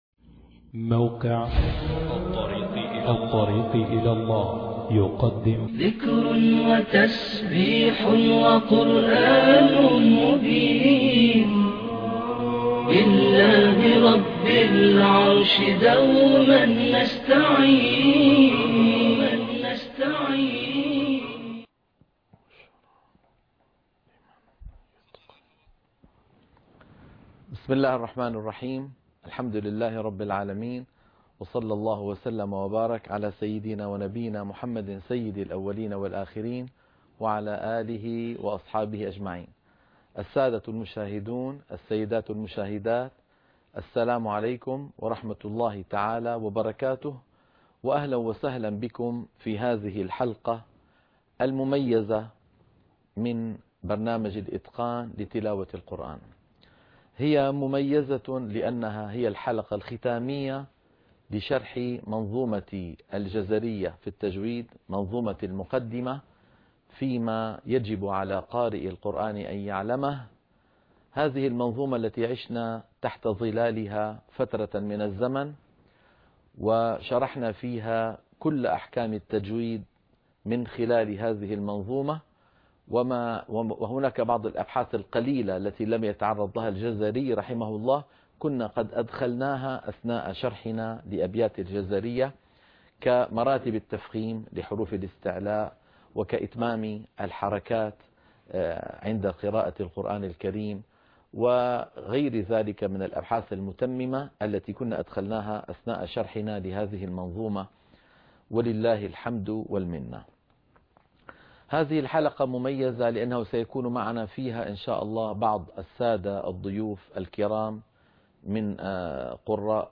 خاتمة المنظومه-لقاء مع قراء مشهوريين بأصوات عذبه( 5/1/2013)الإتقان لتلاوة القرآن - الشيخ أيمن سويد